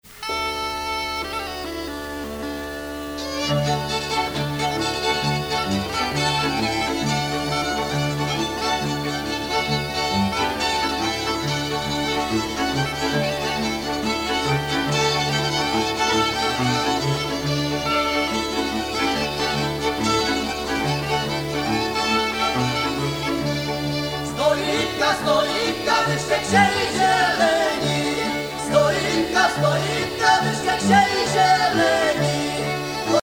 Enquête Lacito-CNRS
Pièce musicale inédite